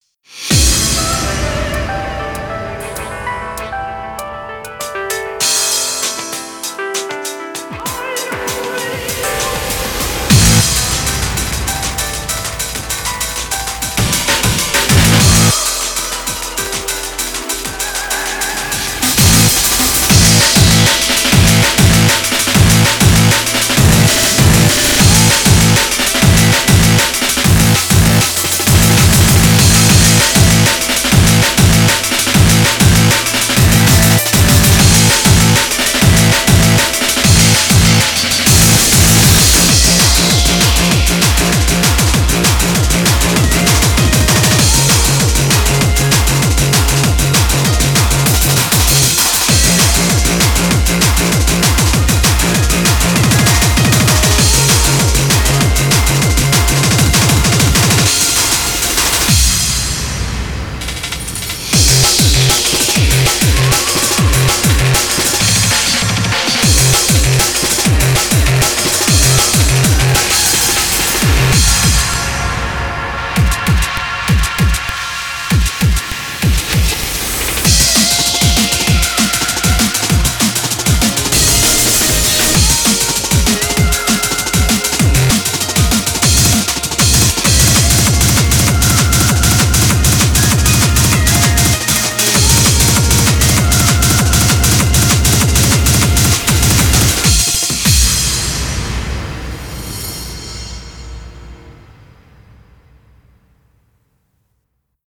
BPM49-196
Audio QualityPerfect (Low Quality)